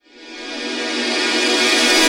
VEC3 FX Athmosphere 08.wav